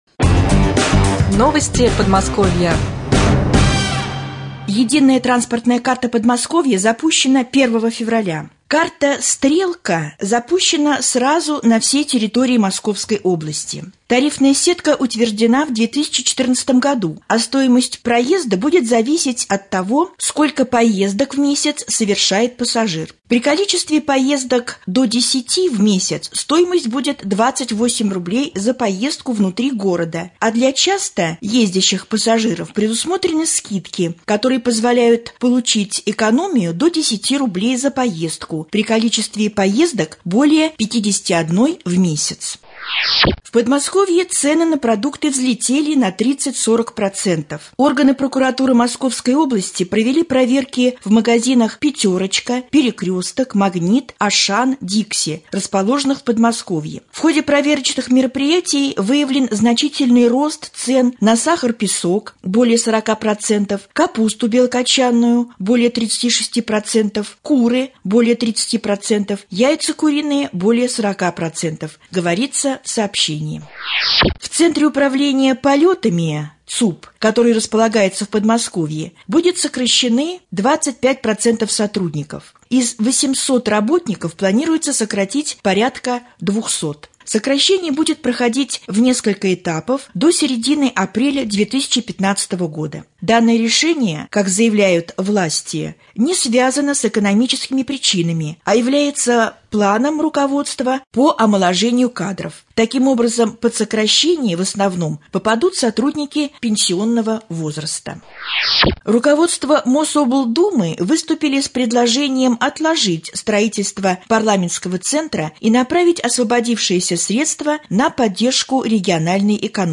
20.02.2015г. в эфире Раменского радио - РамМедиа - Раменский муниципальный округ - Раменское